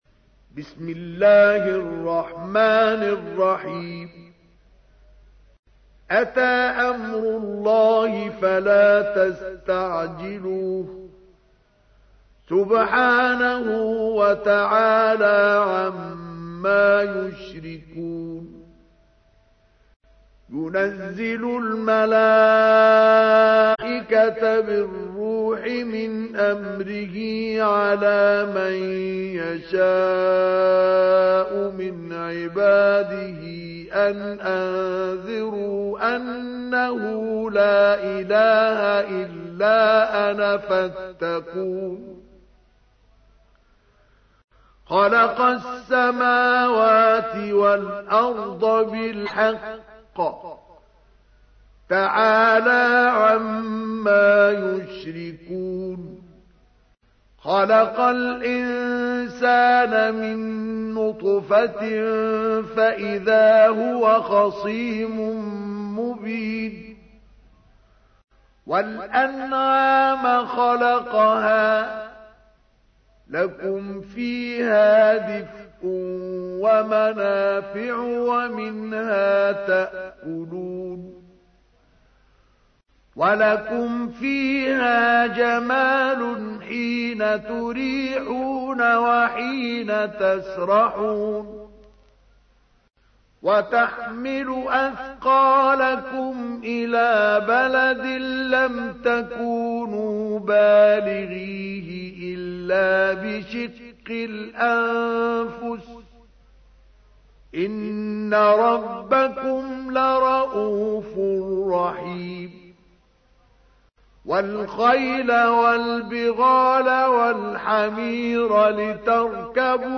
تحميل : 16. سورة النحل / القارئ مصطفى اسماعيل / القرآن الكريم / موقع يا حسين